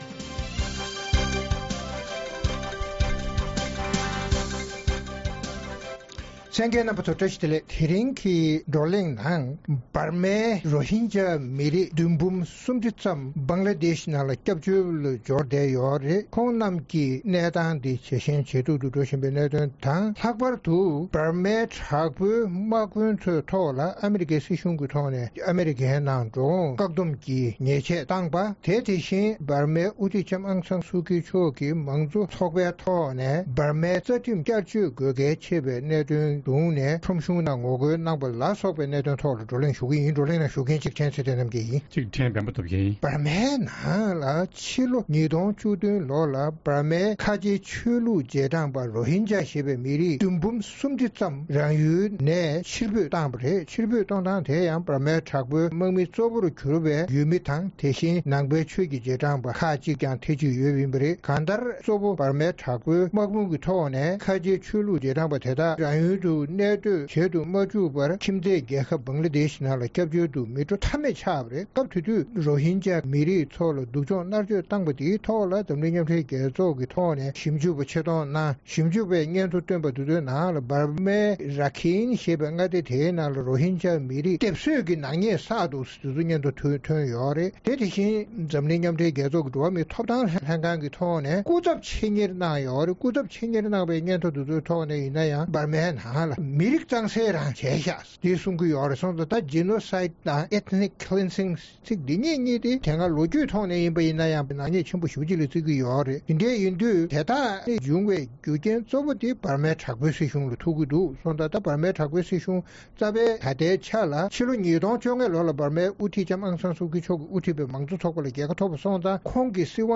དུས་མཚུངས་Aung San Suu Kyi མཆོག་གིས་དབུ་ཁྲིད་པའི་དམངས་གཙོ་ཚོགས་པས་དམག་མིའི་གཞུང་གིས་ཕྱི་ལོ་༢༠༠༨ལོར་གཏན་འབེབས་གནང་བའི་རྩ་ཁྲིམས་ལ་བསྐྱར་བཅོས་དགོས་པའི་ངོ་རྒོལ་གནང་བ་སོགས་ཀྱི་སྐོར་རྩོམ་སྒྲིག་འགན་འཛིན་རྣམ་པས་བགློ་གླེང་གནང་བ་གསན་རོགས་གནང་།